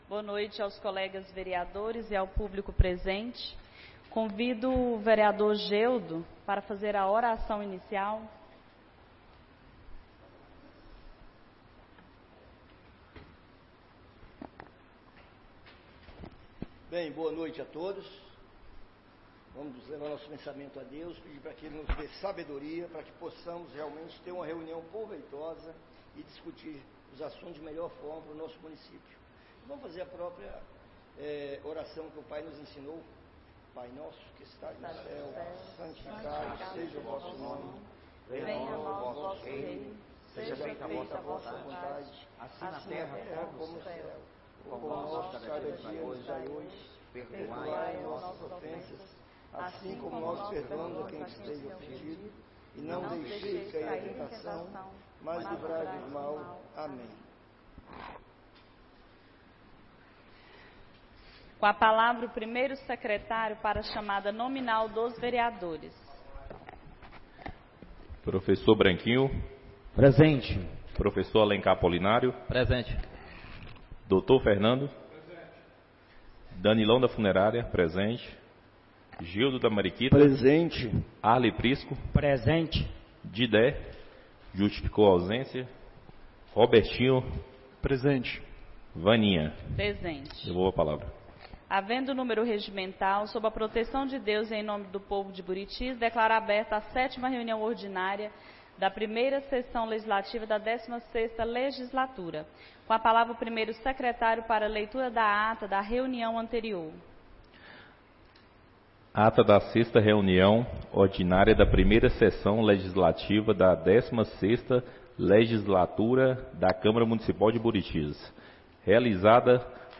7ª Reunião Ordinária da 1ª Sessão Legislativa da 16ª Legislatura - 06-03-25 — Câmara Municipal de Buritis - MG